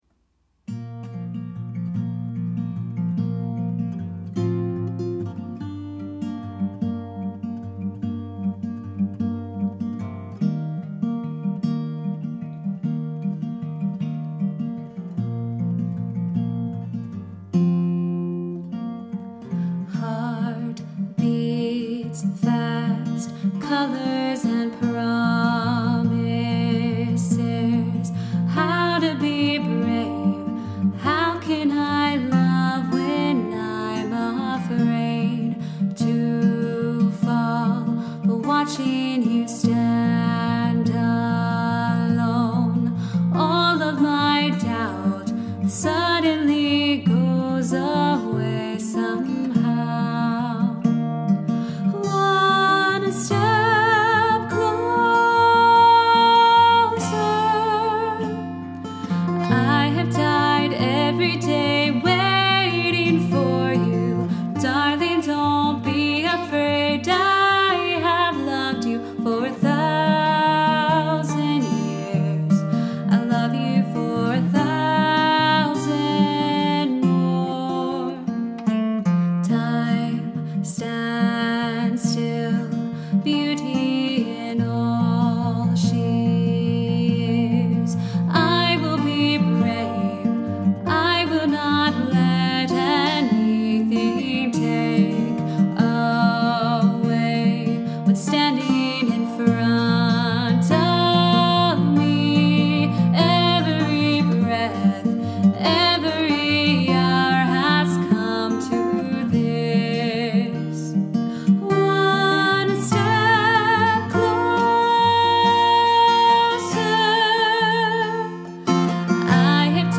in his home studio.&nbsp